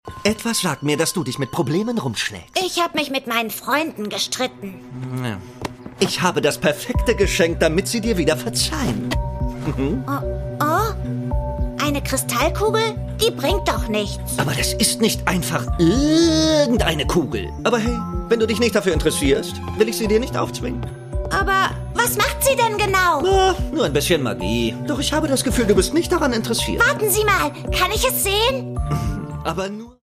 Das Original-Hörspiel zur TV-Serie
Produkttyp: Hörspiel-Download